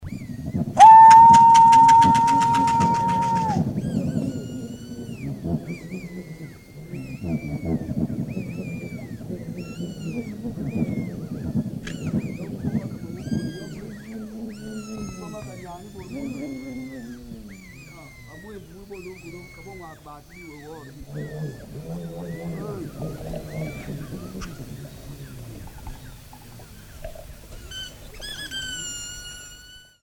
Ocarina
The ocarina is a small spherical flute with 4 to 8 finger holes Apart from its outward appearance, which ranges from a ball to an oval-shaped body, the straight edge-blown flute uses the same playing technique as the ocarina; the air stops at the edge of the instrument and a number of finger holes enables the possibility of producing different tonal pitches.
Kitolori (Bembe), Lofolongo (Saka), Woobo (Leele)